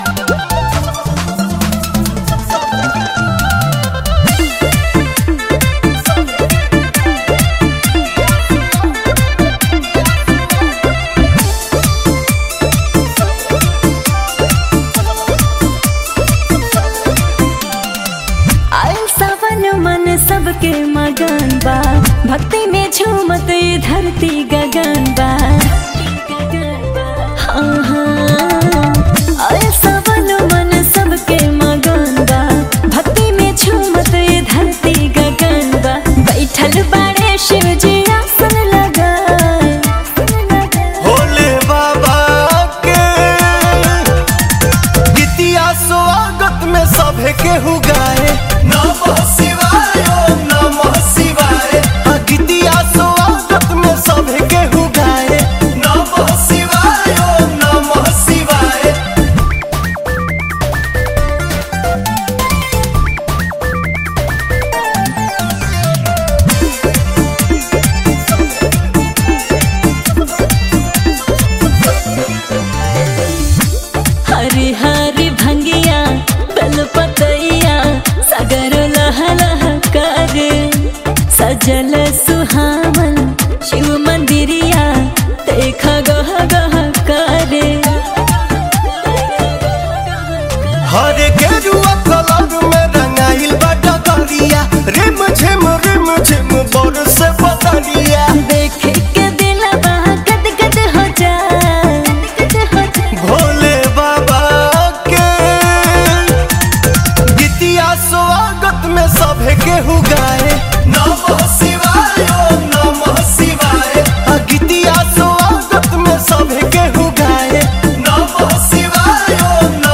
Bhojpuri Songs
New Latest Bolbam Song 2021